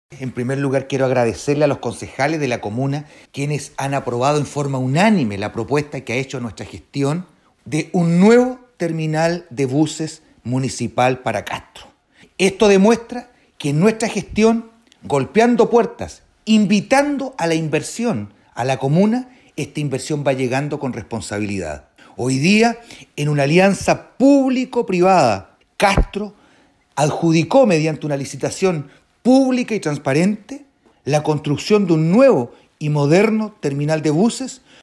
El alcalde Juan Vera, explicó que el proceso fue guiado y asesorado por la Fiscalía Nacional Económica y en donde se presentaron 6 interesados y finalmente concluyó en una sola oferta.